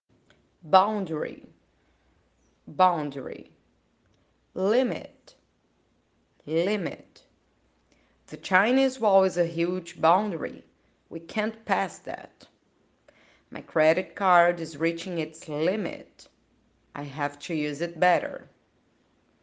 Attention to pronunciation 🗣